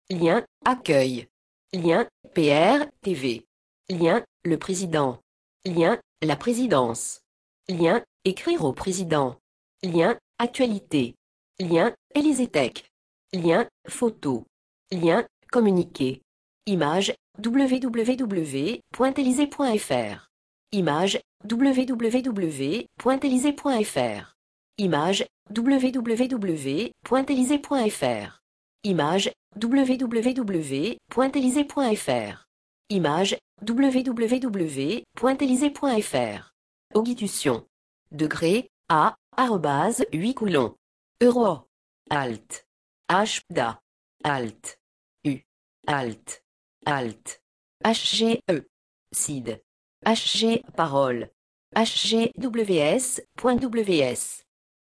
je recommande particulièrement l’écoute (lien vers le synthétiseur vocal utilisé pour l’occasion, qui est à se tordre de rire si l’on n’est pas concerné par ce genre d’handicap.